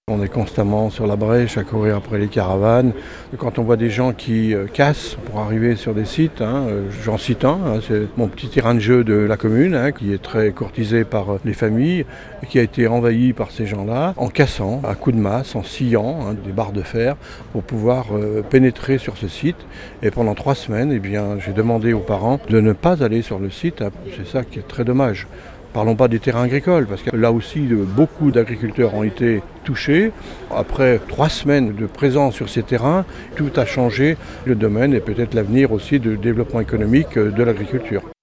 Vendredi 5 septembre, une trentaine d'entre eux ont manifesté devant la préfecture d'Annecy pour réclamer plus de fermeté contre les installations illégales des gens du voyage.
L'un des maires du Genevois, particulièrement confronté au problème, ne mâche pas ses mots.